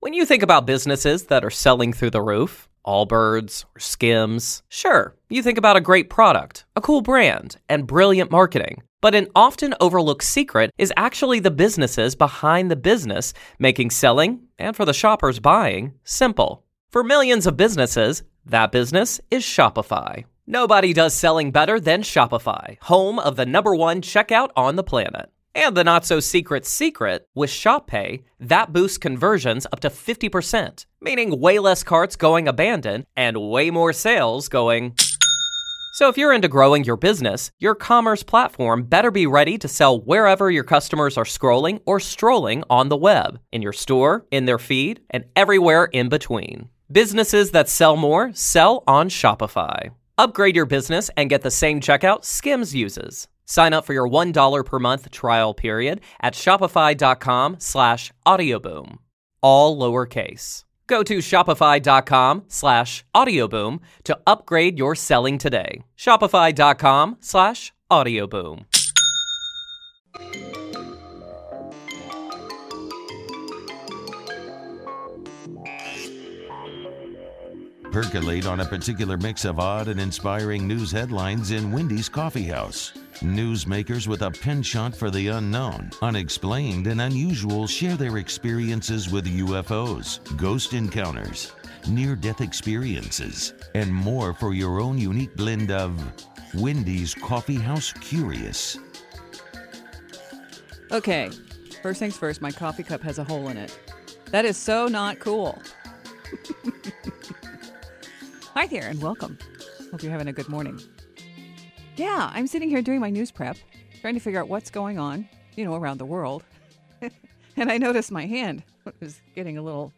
Aired on KCMO Talk Radio